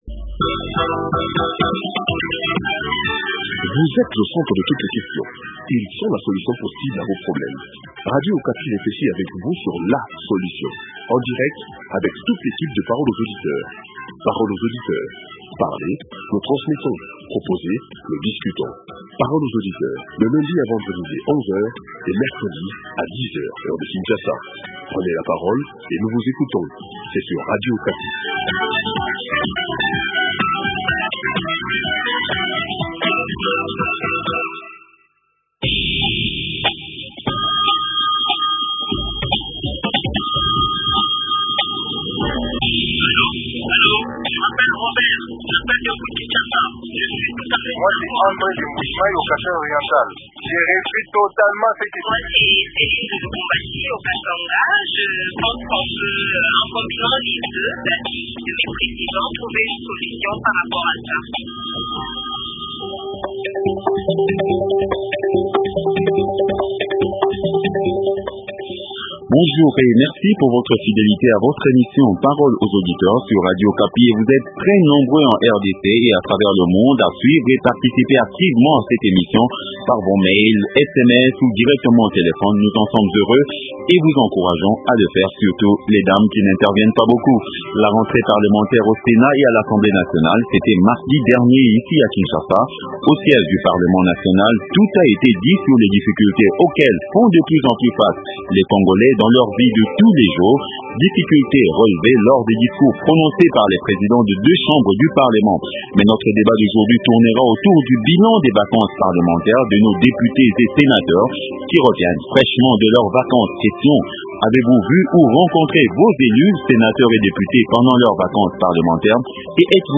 - Mme Vicky Katumwa, député nationale.